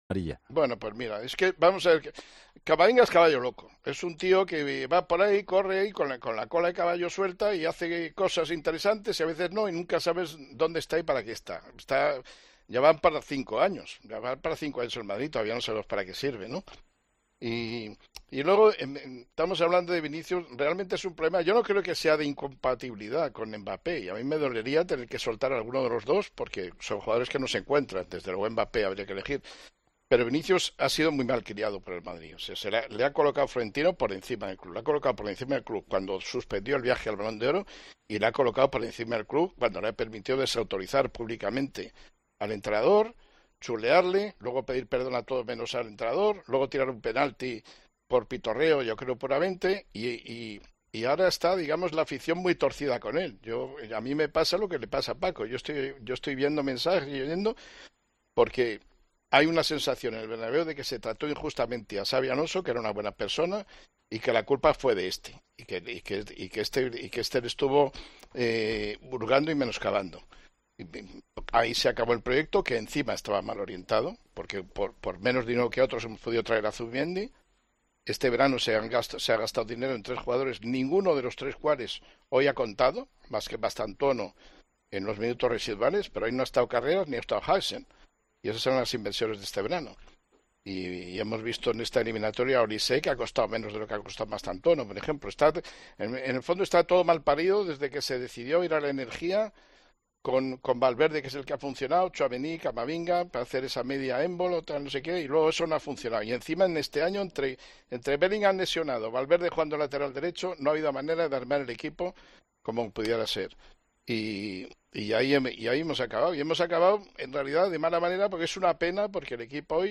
Juanma Castaño analiza el futuro del Real Madrid con el tertulión especial tras la eliminación del Real Madrid de la Champions League: Entrenador, fichajes y proyecto